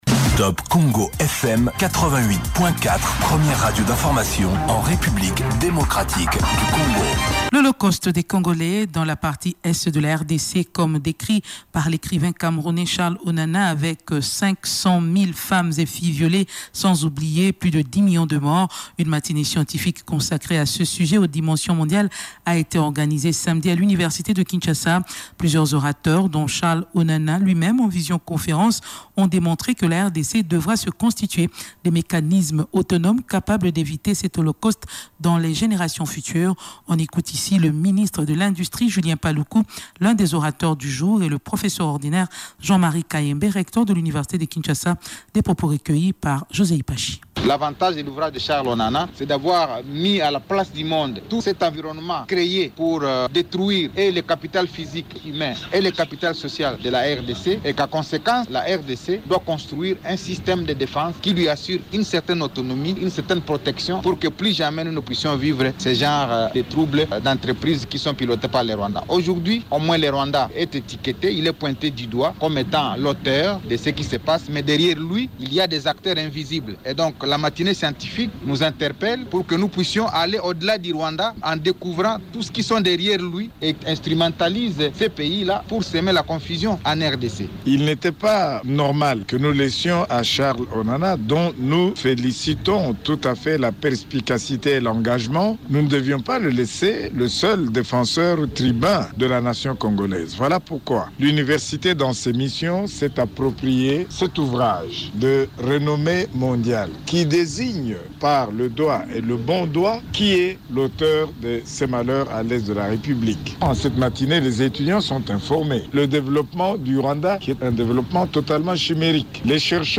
ci dessous, le commentaire de TopCongo FM
audio-top-congo-sur-holocauste-au-Congo.mp3